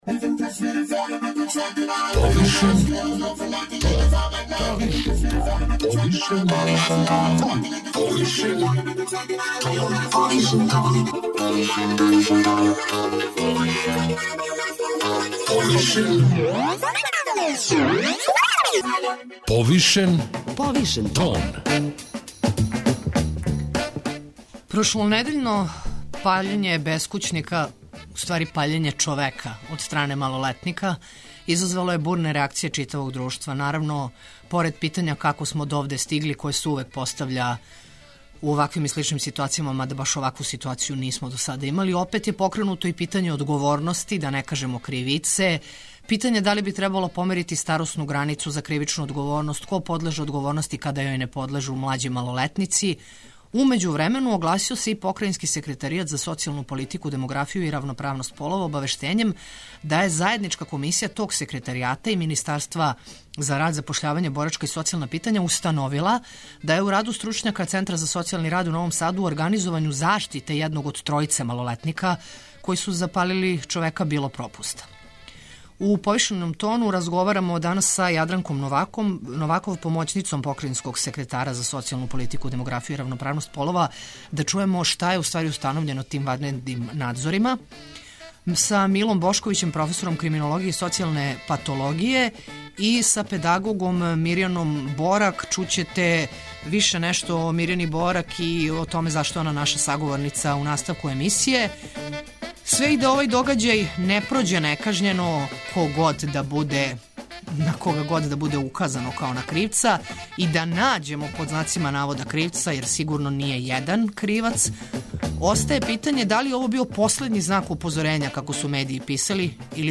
преузми : 52.49 MB Повишен тон Autor: Београд 202 Од понедељка до четвртка отварамо теме које нас муче и боле, оне о којима избегавамо да разговарамо aли и оне о којима разговарамо повишеним тоном.